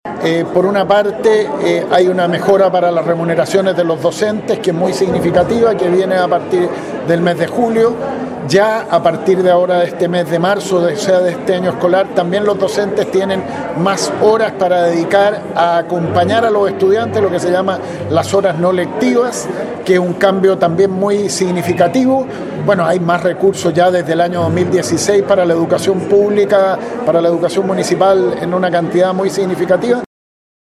Por su parte el Secretario Ejecutivo de la Reforma, Andrés Palma, resaltó el esfuerzo puesto por el Ministerio para fortalecer la educación pública.